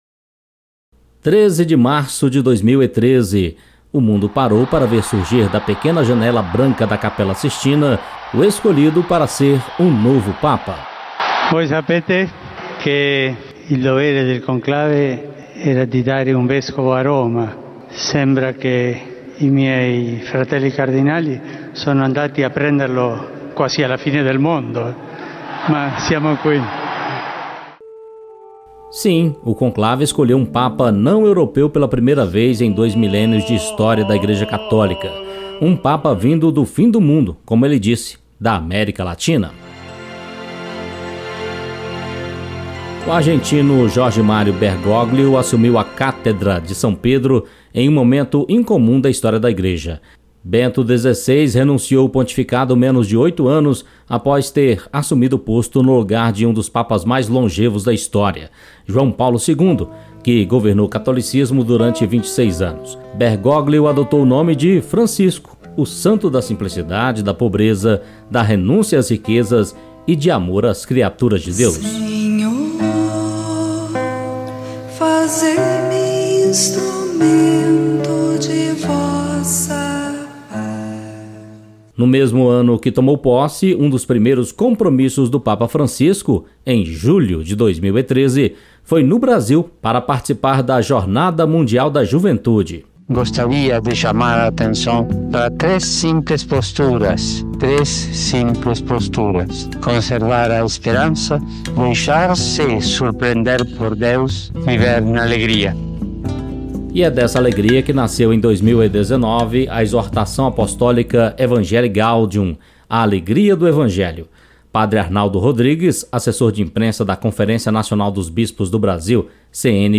Reportagem especial: Francisco - O papa que fez a igreja se abrir ao mundo